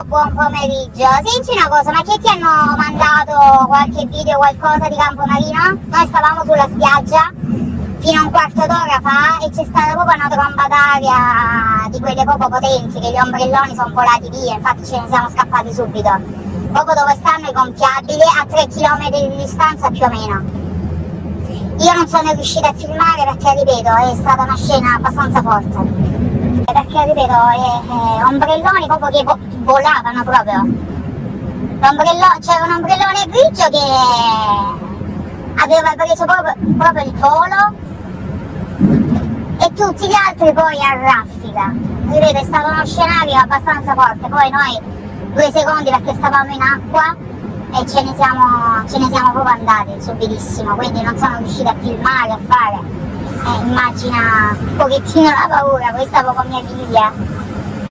Clicca qui e ASCOLTA L'AUDIO DELLA TESTIMONE